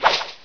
slash2.wav